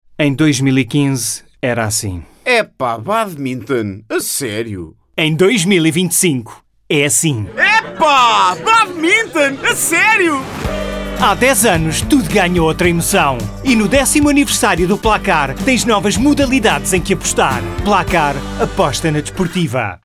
SPOT 1